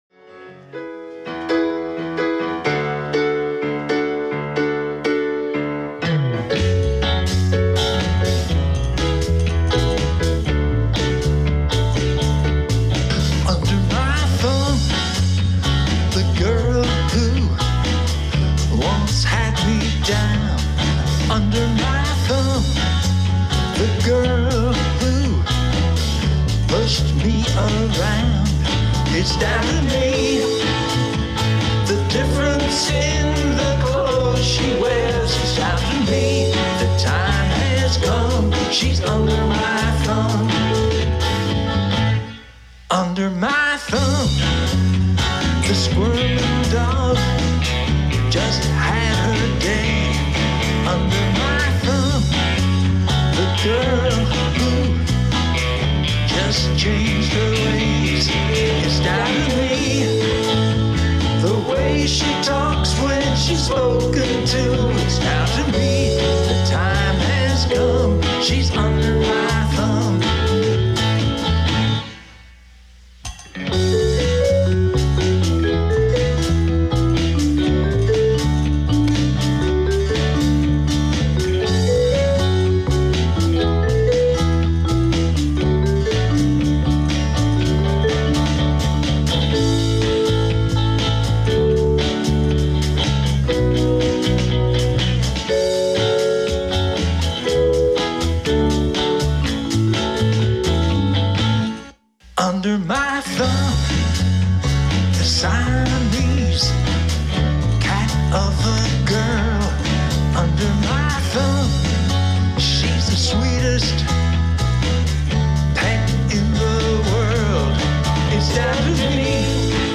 This is a live recording by my band about 3 years ago.
is that a xylophone solo? or vibes? whatever it is